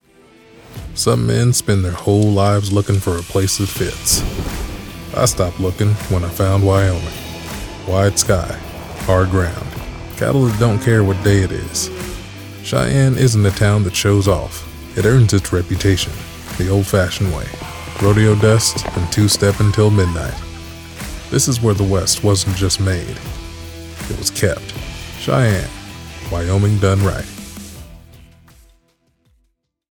Character, Cartoon and Animation Voice Overs
Yng Adult (18-29) | Adult (30-50)